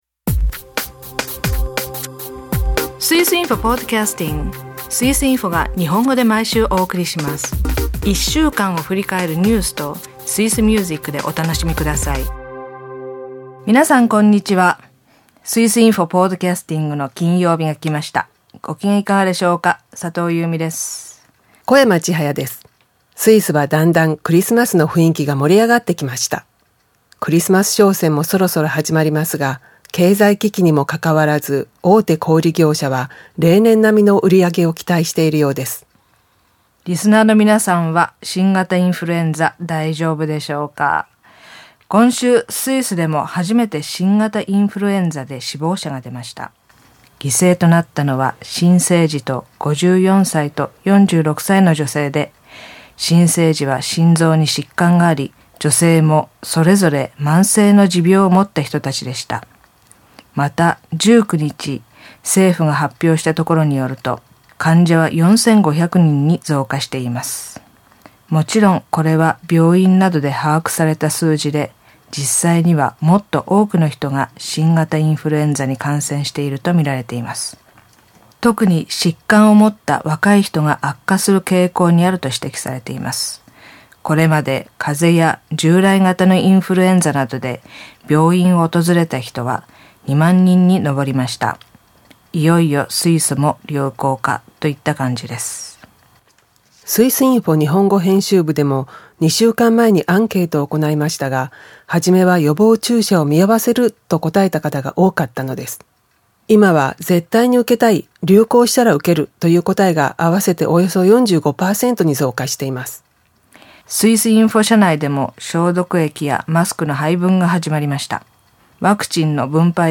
UBS銀行はアメリカ政府に対し、4450件の顧客情報を開示する見込み。チューリヒ中央駅の地下では大規模な工事が進行中です。今週の1曲はアルプホルンの「Bim Gumpstei」をどうぞ。